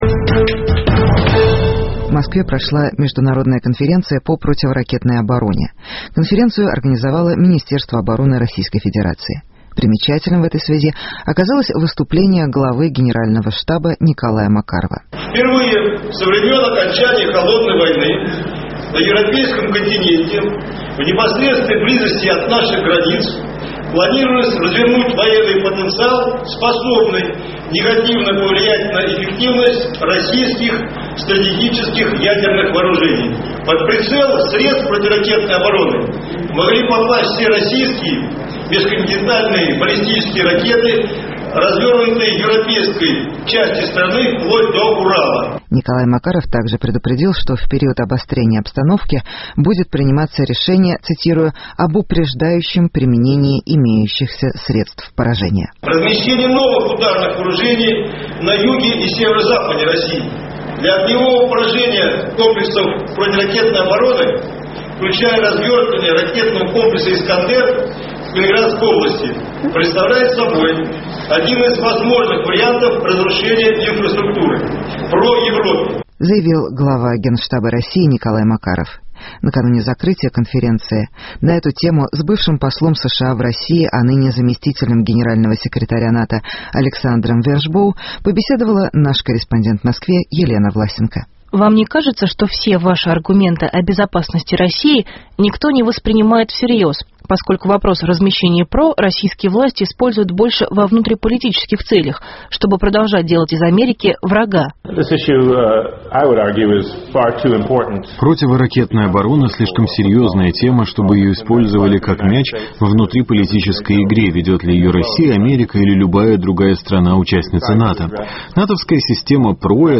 Россия: ПРО и contra. Интервью с заместителем Генерального секретаря НАТО Александром Вершбоу